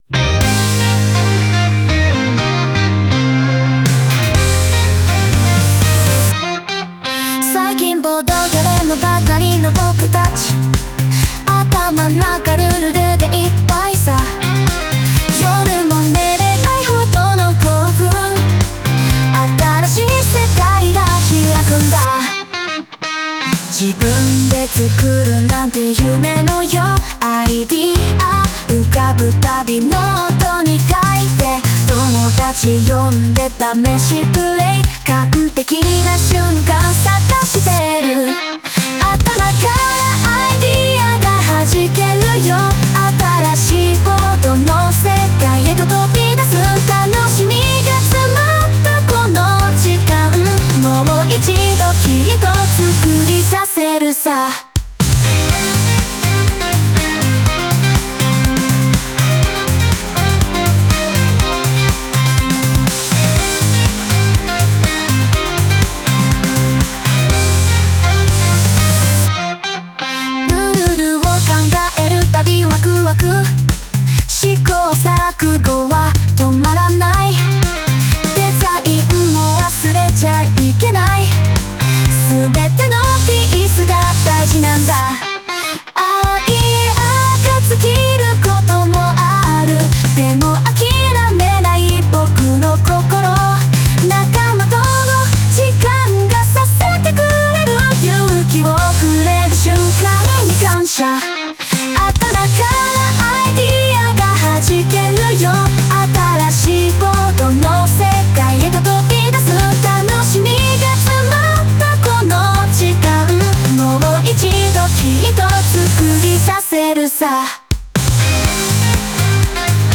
心温まるメロディと歌詞で知られるボーカリスト。